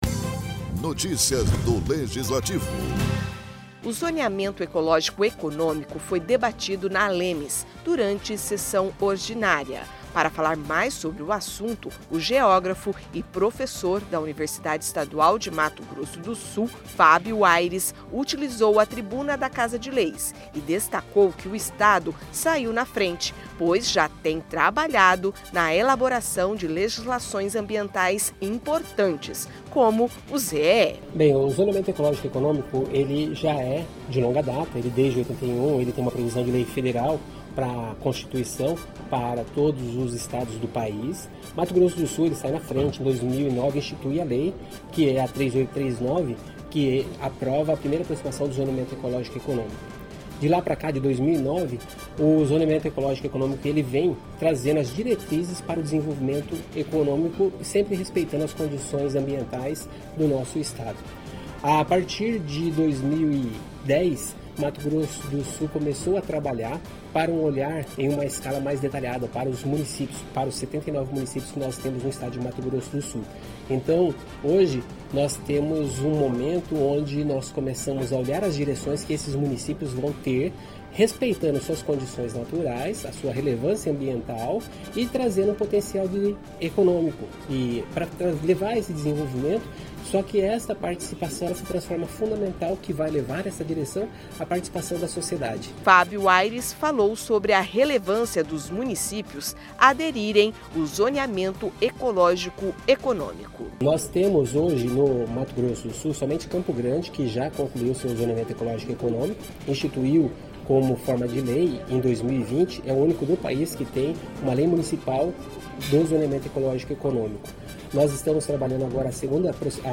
Na ALEMS, geógrafo fala da importância do Zoneamento Ecológico e Econômico para MS